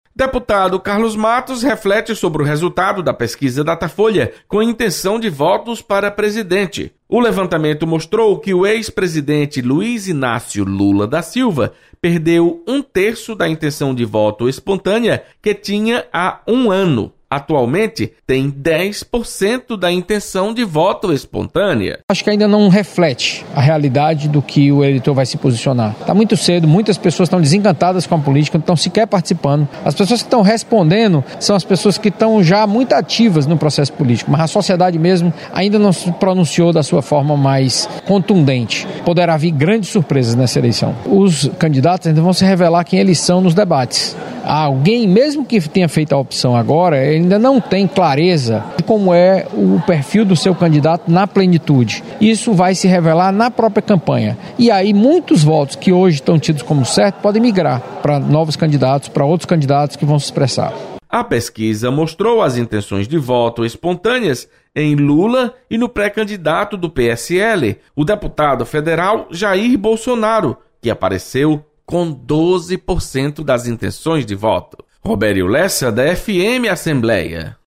Deputado Carlos Matos comenta pesquisa de intenção de voto para presidente.